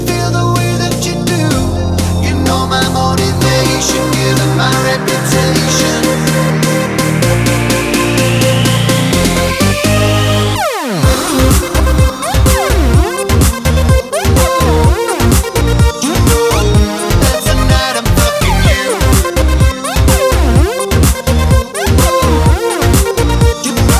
no rap Pop